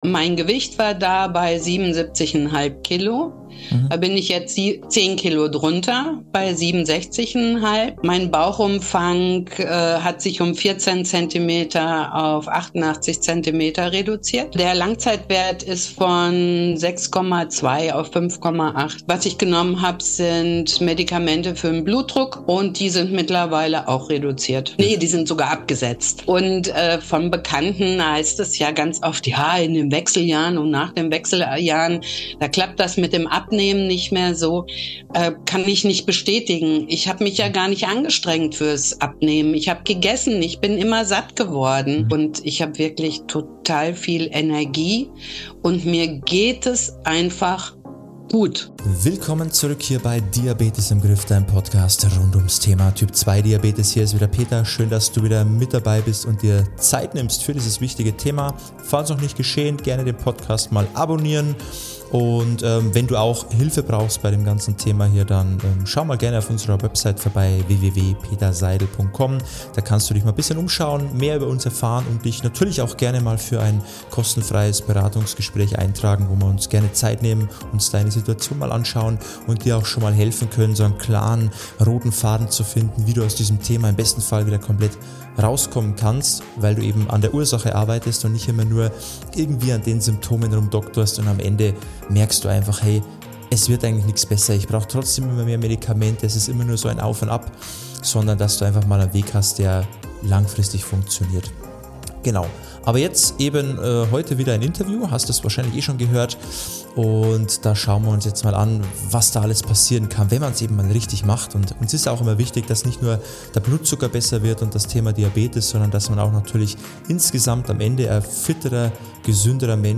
Viel Freude mit diesem inspirierenden Interview!